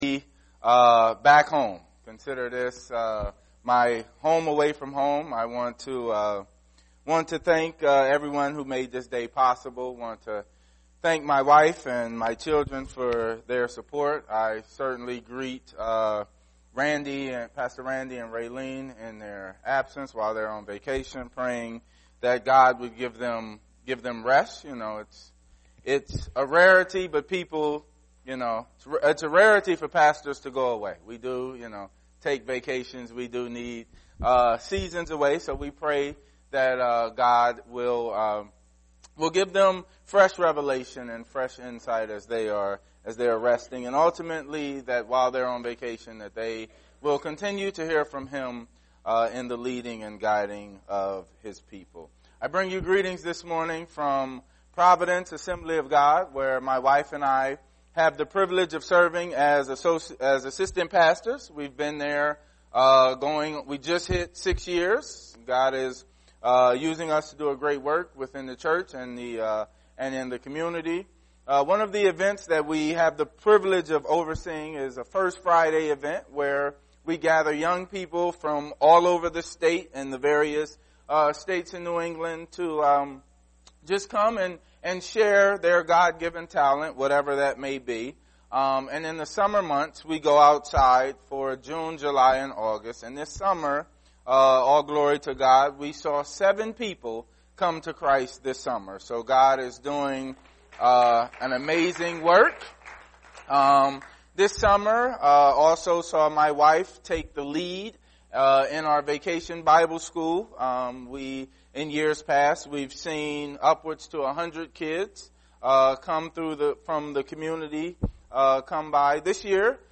Guest Speaker
Sermons